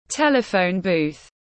Bốt điện thoại công cộng tiếng anh gọi là telephone booth, phiên âm tiếng anh đọc là /ˈtel.ɪ.fəʊn ˌbuːð/.
Telephone booth /ˈtel.ɪ.fəʊn ˌbuːð/